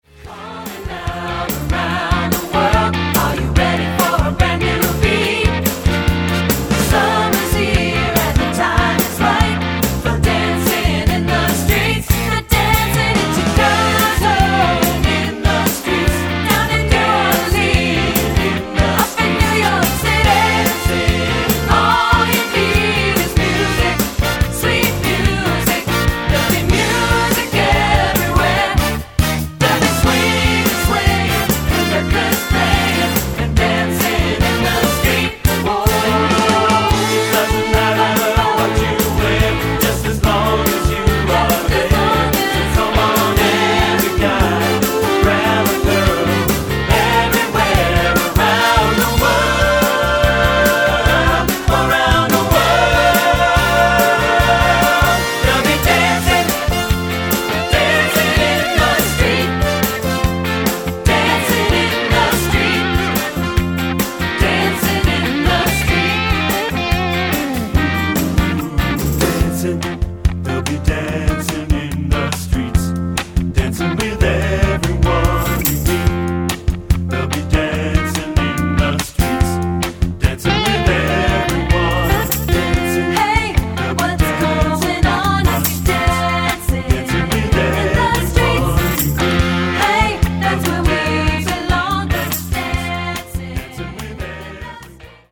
Choral 50's and 60's Pop
SATB